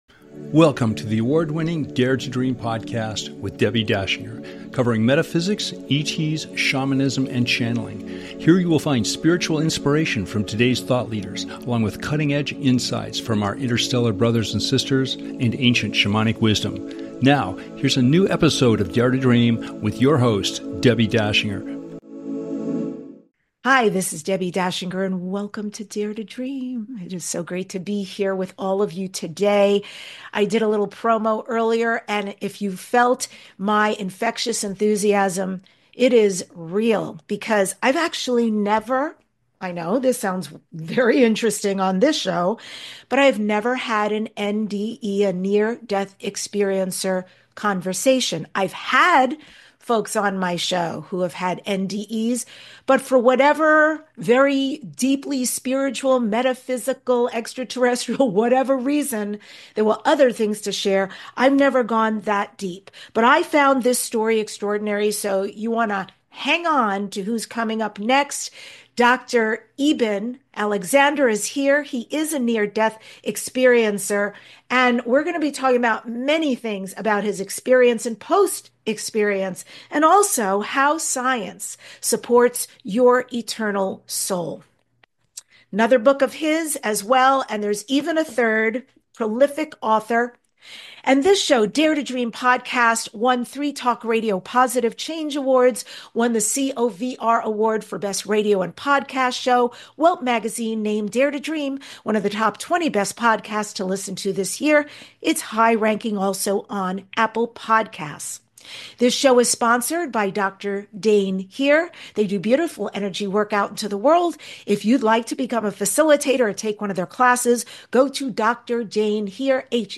Guest, Dr Eben Alexander, NDE Experiencer, Academic neurosurgeon with research interest in phenomenon of consciousness